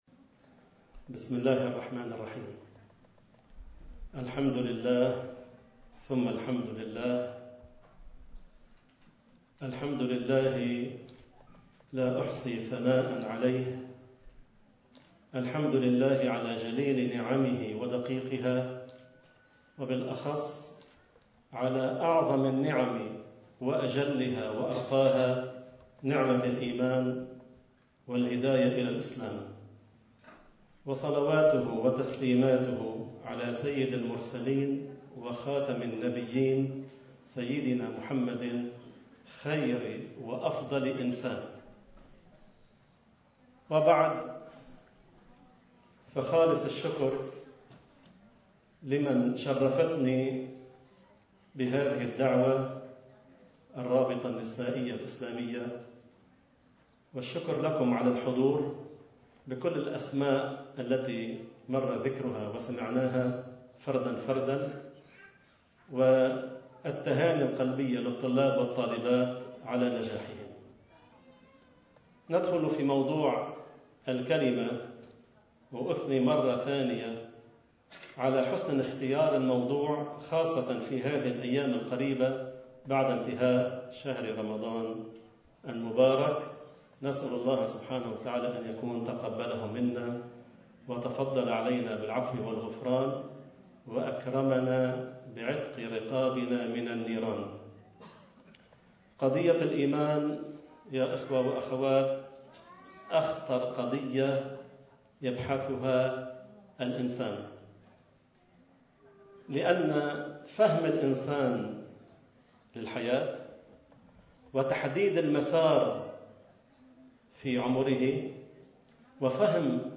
كلمة
خلال حفل التكريم الذي نظمته الرابطة النسائية الإسلامية في طرابلس لطلابها المكفولين في مرحلتَي الشهادة المتوسطة والثانوية.